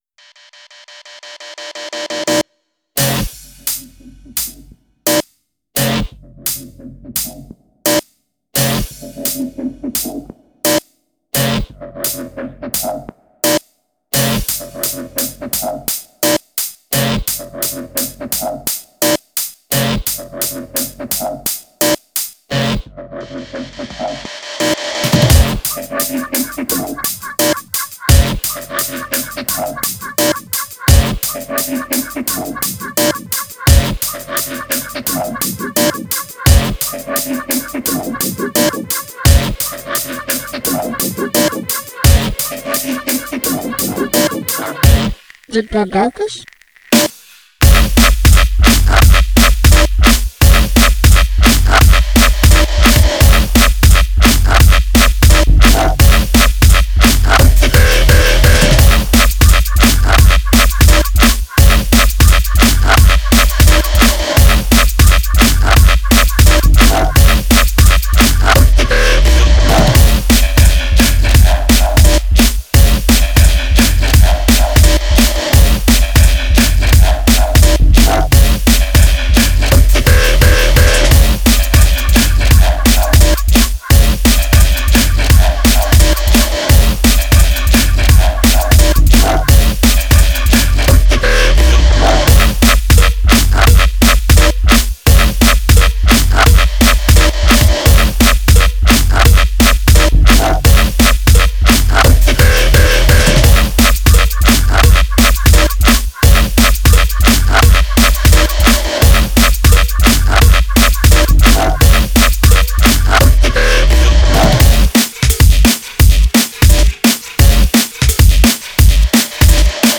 Style: Dubstep, Drum & Bass
Quality: 320 kbps / 44.1KHz / Full Stereo